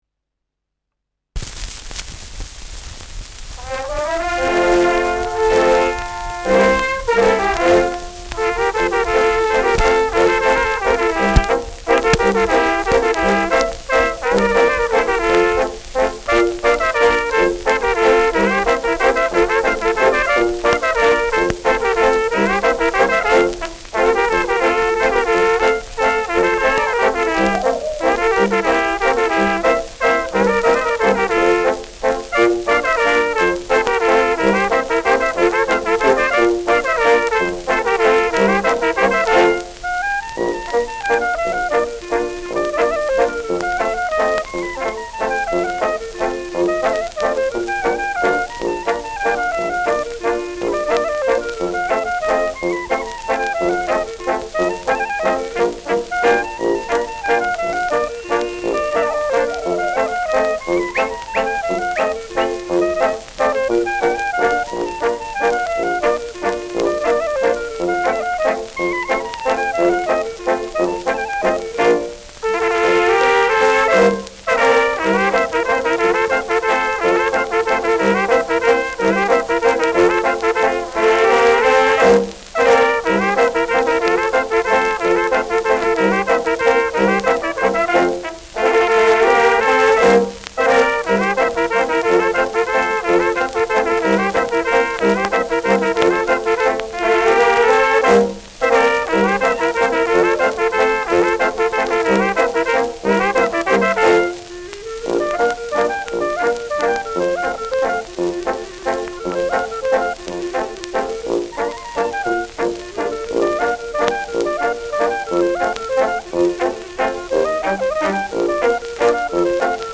Schellackplatte
leichtes Rauschen : Knacken
Dachauer Bauernkapelle (Interpretation)
Mit Pfiffen.